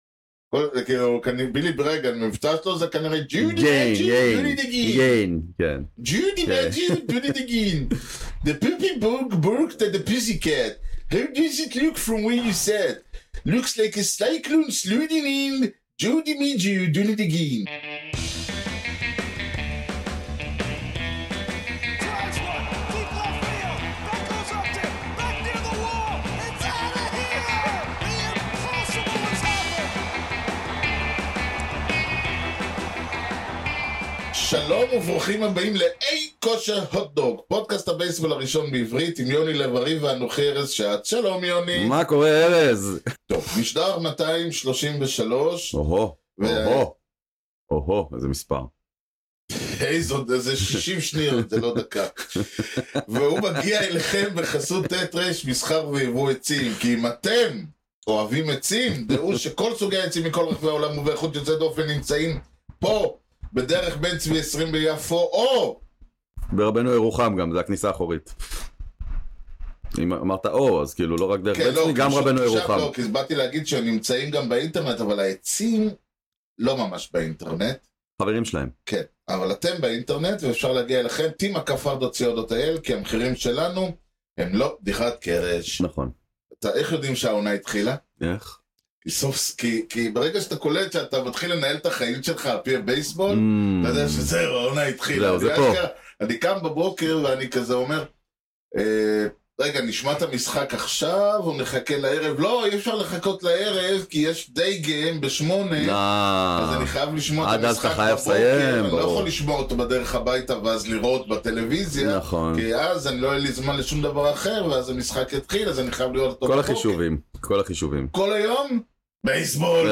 וכמובן נאזין לשיר בייסבול באווירת אלט-אינדי-פולק-קאנטרי בפינת ״בלדה לחובט״